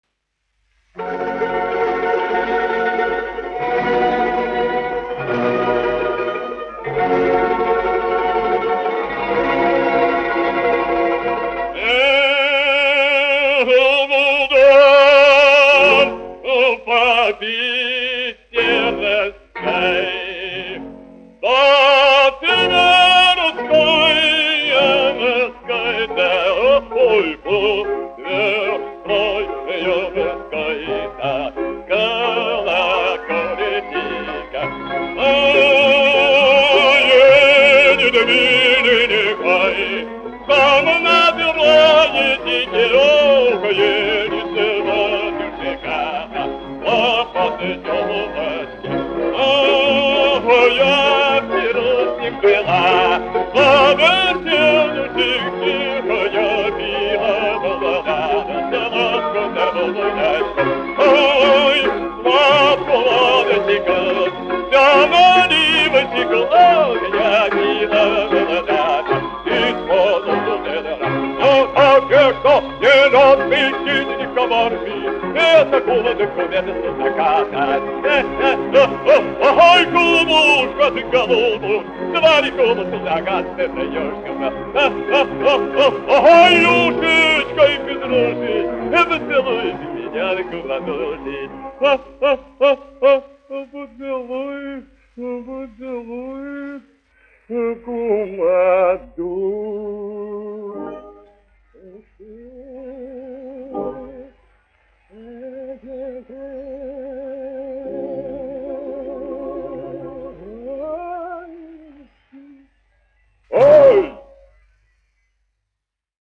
Вдоль по Питерской : русская песня
Шаляпин, Федор Иванович, 1873-1938, dziedātājs
1 skpl. : analogs, 78 apgr/min, mono ; 25 cm
Krievu dziesmas
Skaņuplate